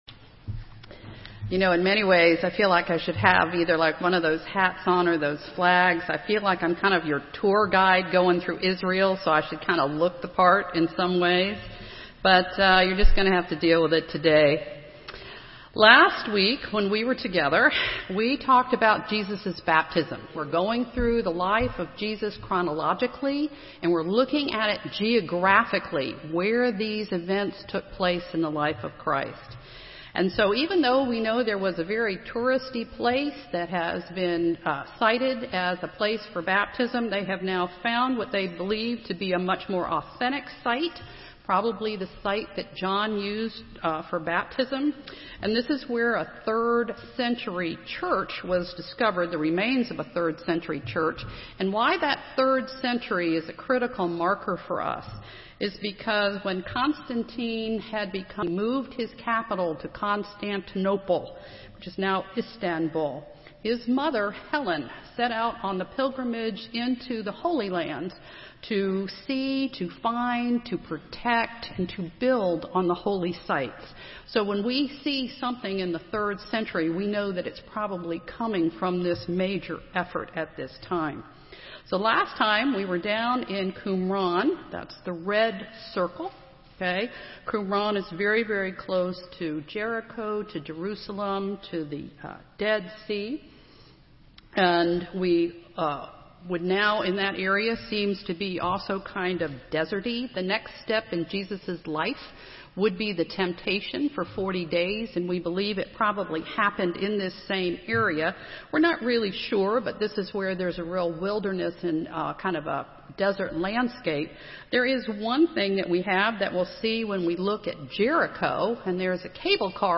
Sermons | Forest Hills Presbyterian Church